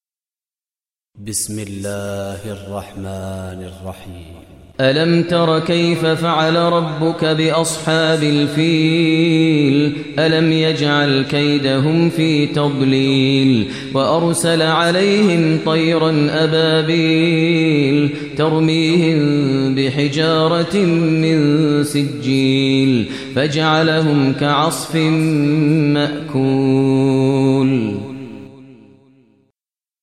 Download Surah Fil Tilawat Maher al Mueaqly